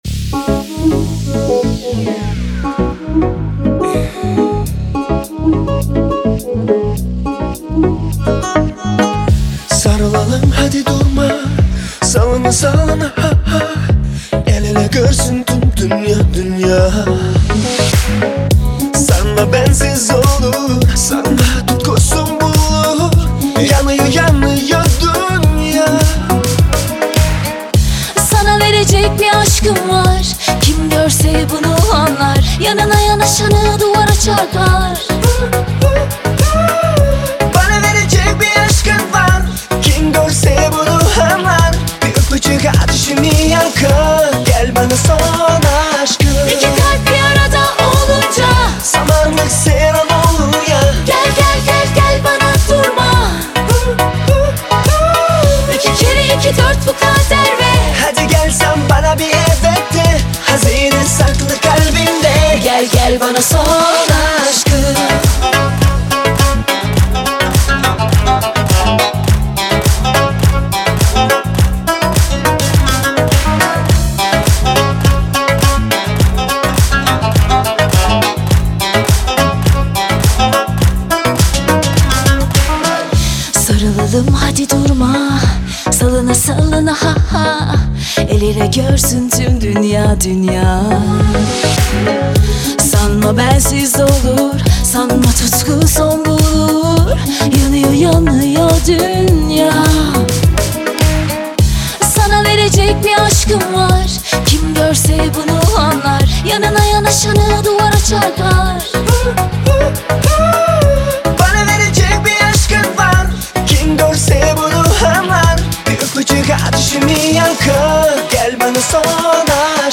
это романтическая композиция в жанре поп